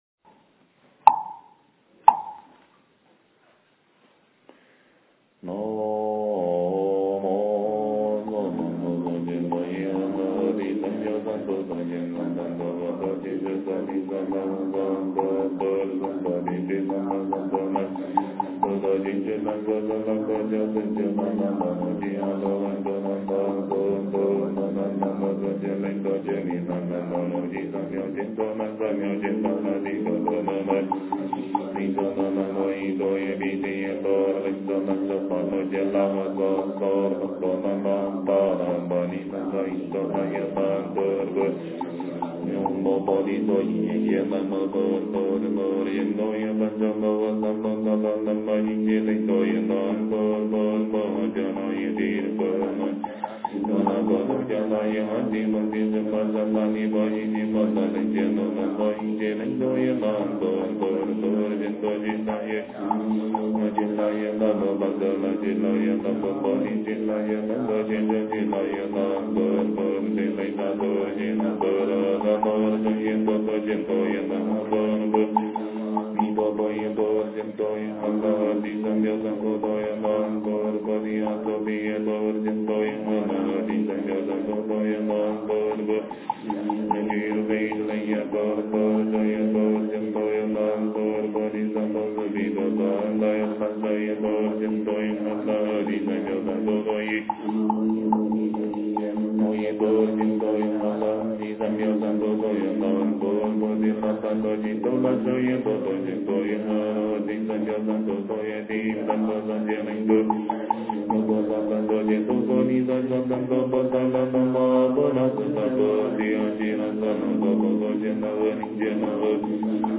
经忏
佛音 经忏 佛教音乐 返回列表 上一篇： 超度仪轨--僧团 下一篇： 阿弥陀佛大乐心咒--海涛法师 相关文章 大乘金刚般若宝忏法卷中--金光明寺 大乘金刚般若宝忏法卷中--金光明寺...